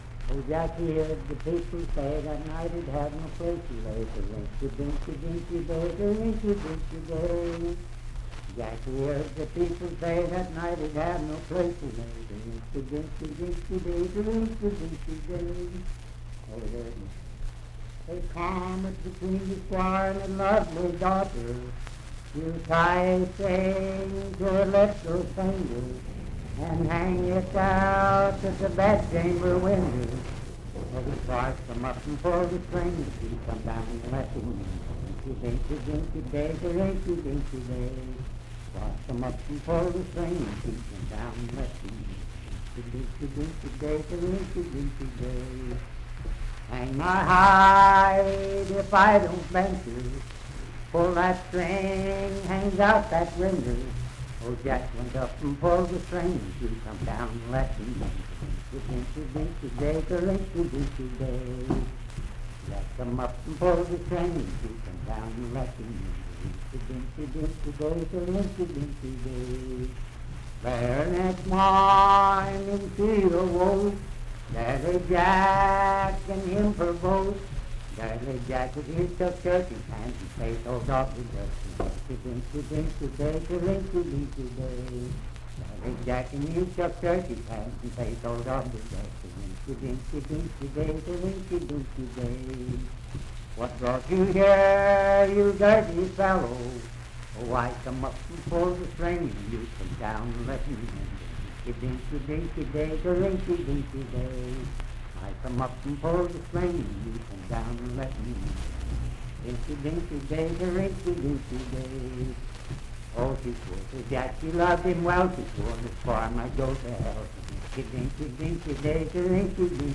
Unaccompanied vocal music and folktales
Bawdy Songs
Voice (sung)
Parkersburg (W. Va.), Wood County (W. Va.)